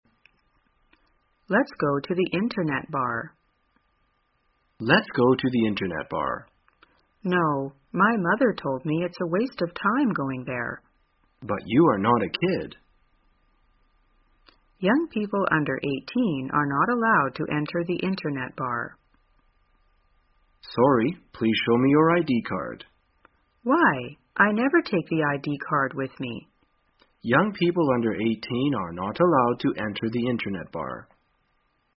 在线英语听力室生活口语天天说 第183期:怎样谈论去网吧的听力文件下载,《生活口语天天说》栏目将日常生活中最常用到的口语句型进行收集和重点讲解。真人发音配字幕帮助英语爱好者们练习听力并进行口语跟读。